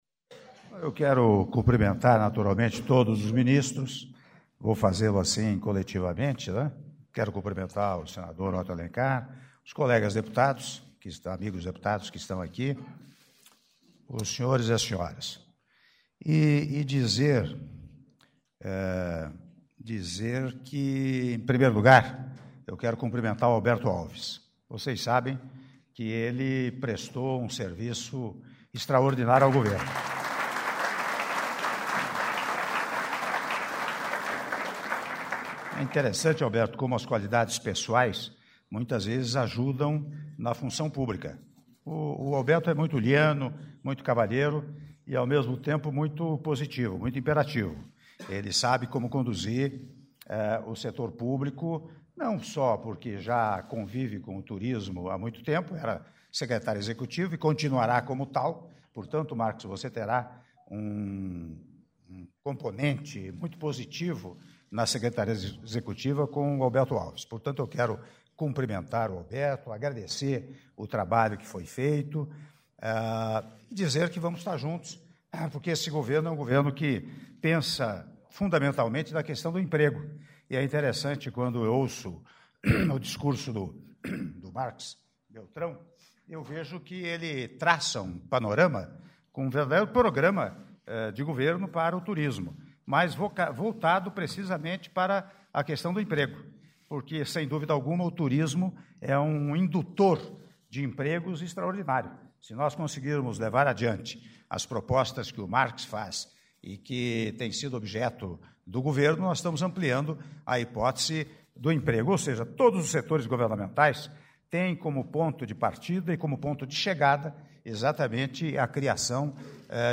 Áudio do discurso do presidente da República, Michel Temer, durante cerimônia de Posse do novo Ministro de Estado do Turismo - (08min10s) - Brasília/DF